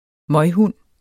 Udtale [ ˈmʌjˌhunˀ ]